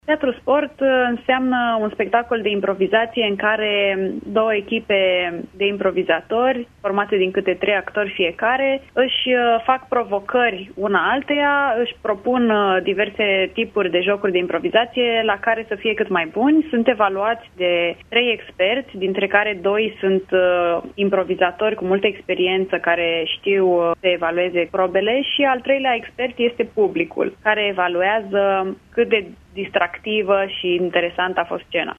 a explicat în emisiunea Imperativ de la Radio Iași ce înseamnă acest campionat: